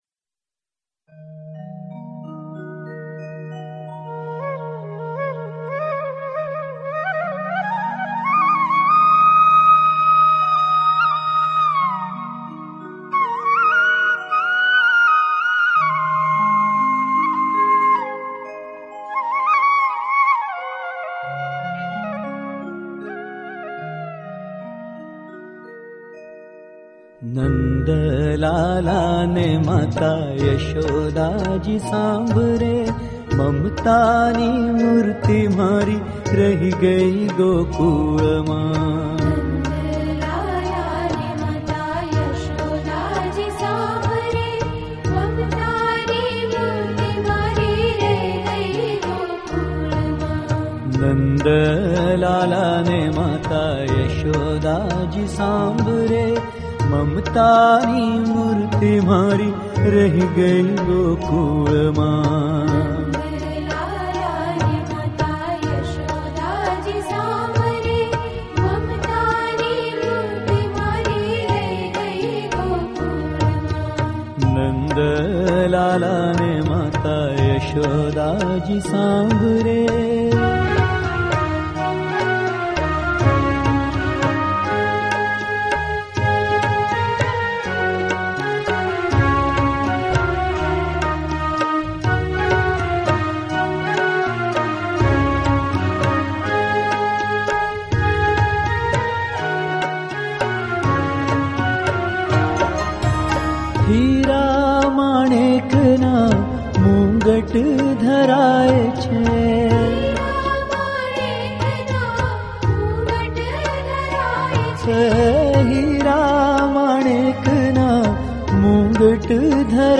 Published in અન્ય ગાયકો, ઓડિયો and ભજન